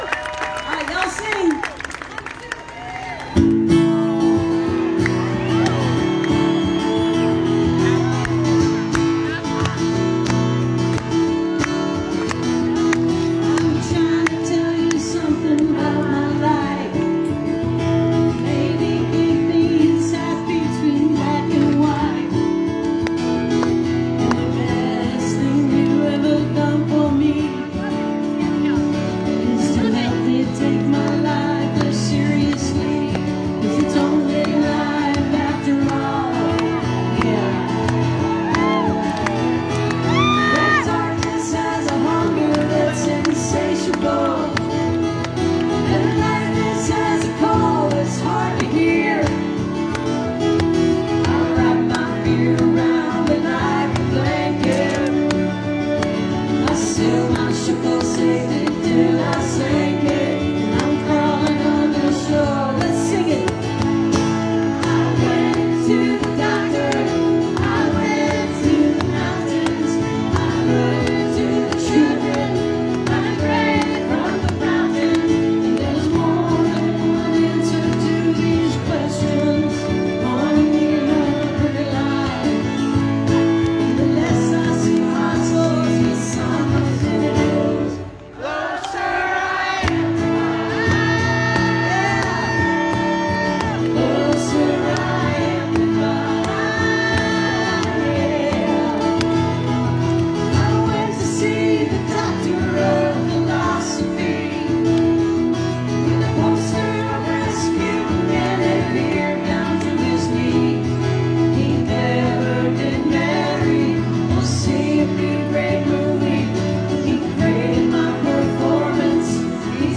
(captured from the facebook livestream)